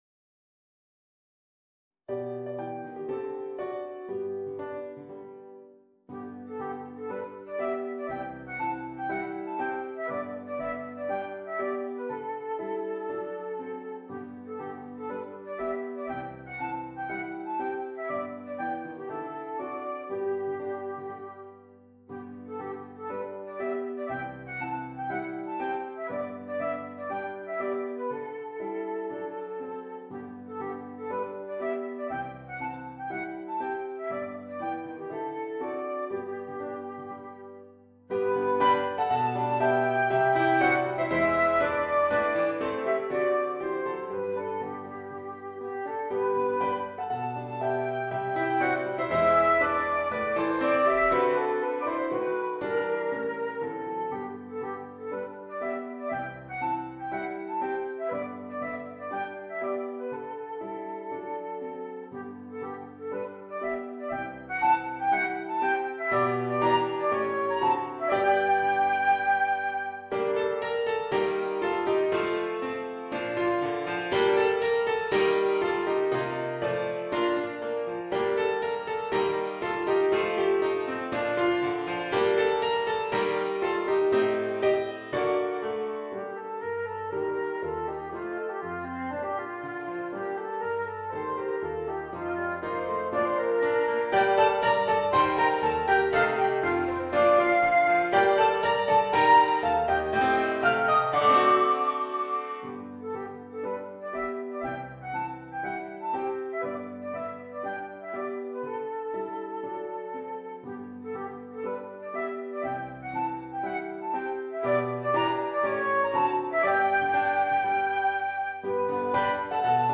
フルート＋ピアノ